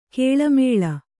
♪ kēḷa mēḷa